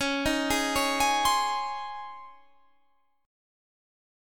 DbM7sus2 Chord
Listen to DbM7sus2 strummed